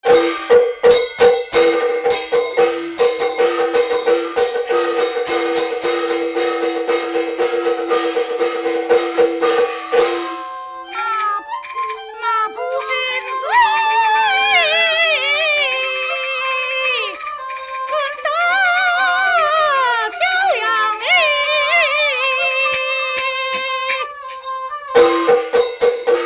戲曲 - 三進宮選段2（緊板） | 新北市客家文化典藏資料庫